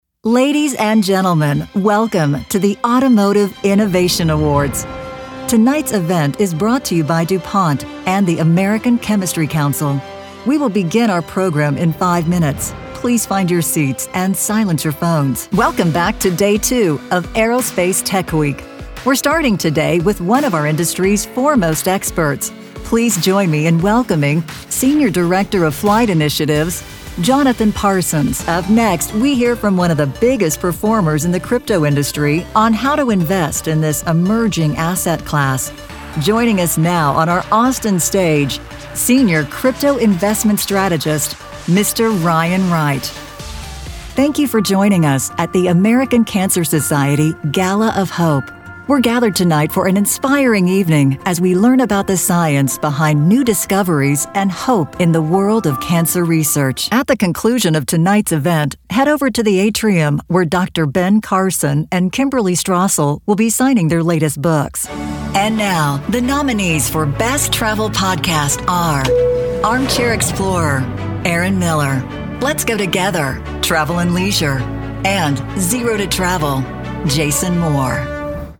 Warm, Confident, Elegant.
Live Announce